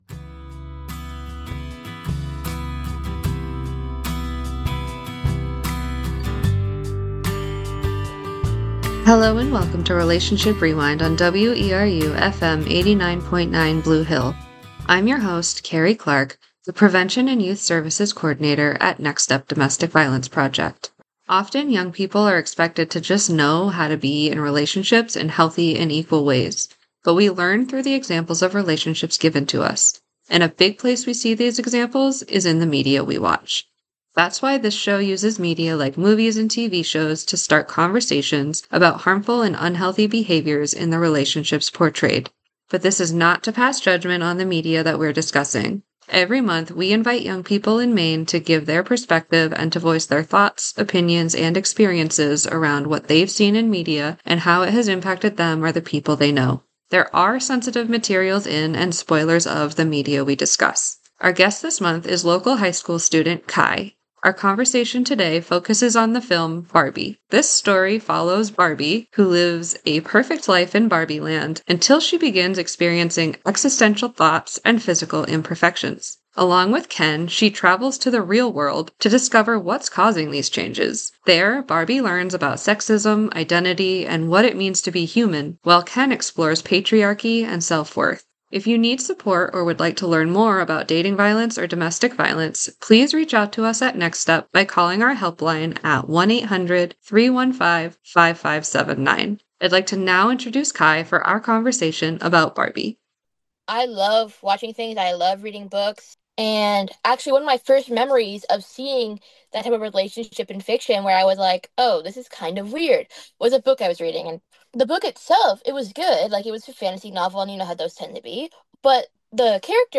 MP3 Audio Archive Recordings (aka Podcasts) of all locally produced spoken word programming from Community Radio WERU 89.9 FM Blue Hill, Maine - Part 41